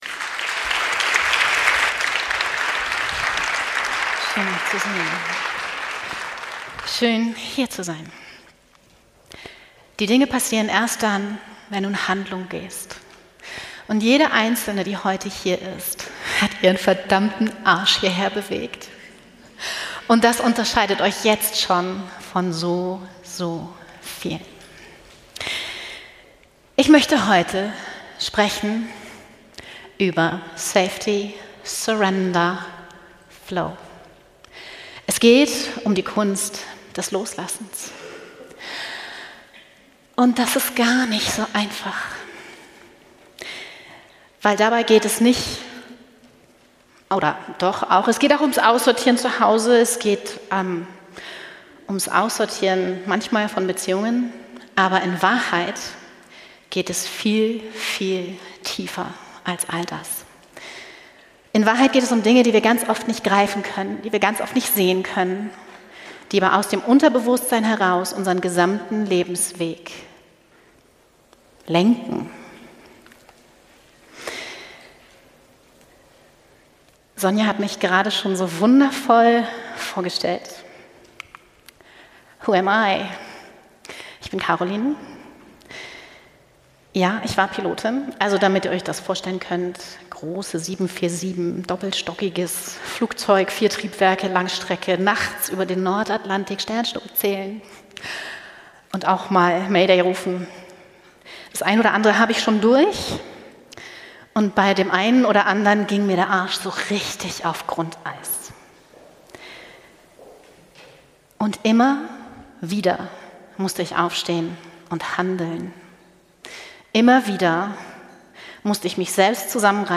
In diesem Live-Vortrag